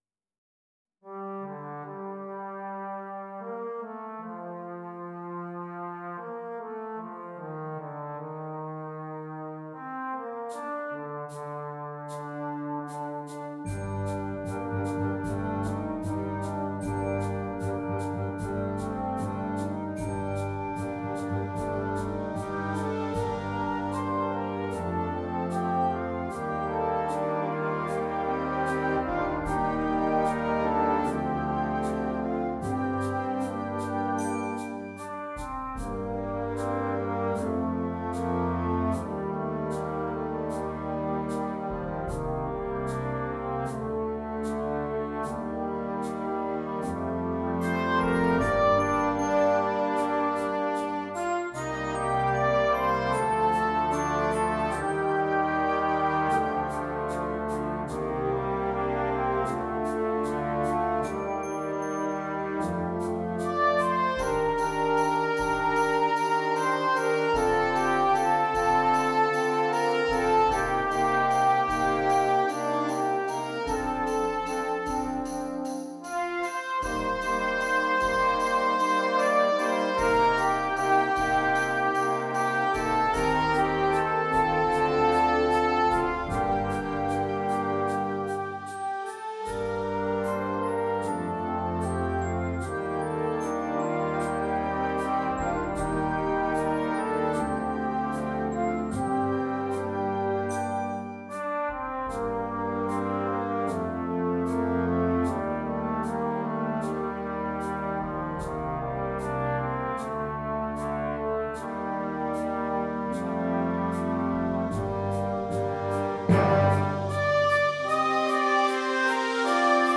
Flex Band